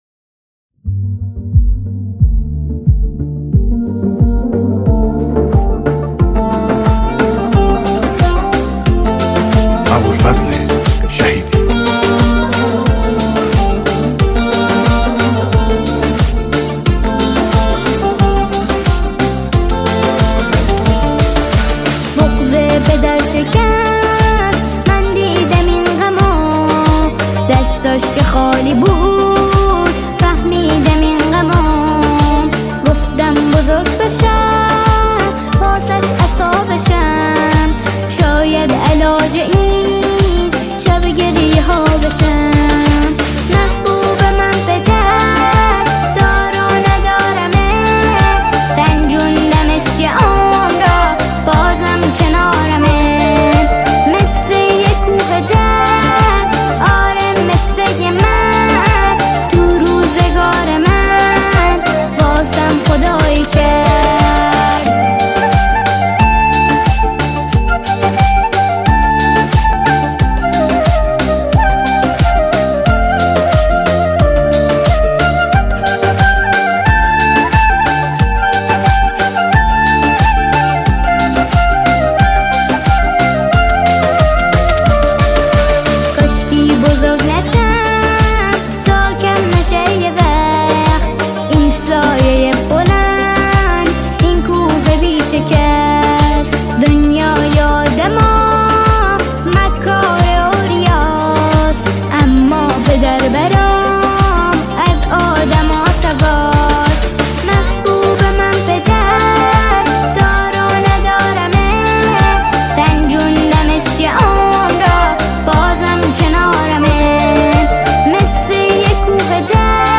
آهنگ مشهدی